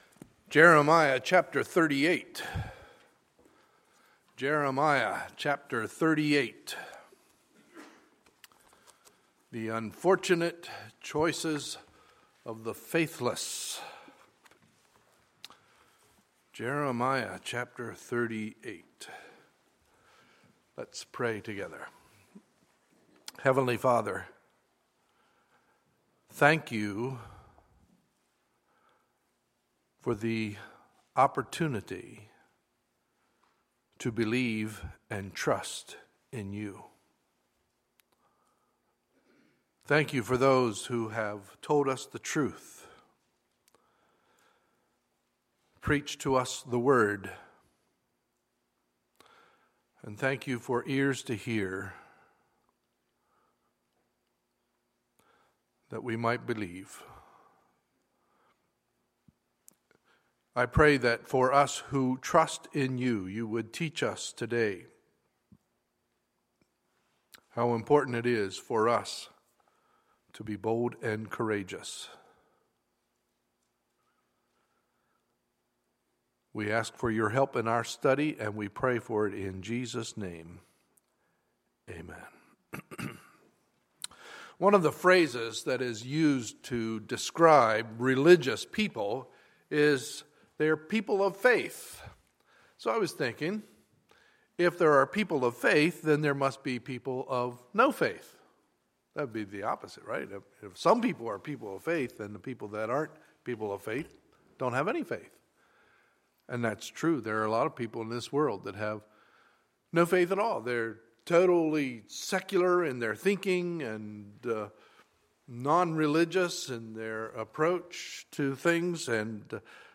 Sunday, November 8, 2015 – Sunday Morning Service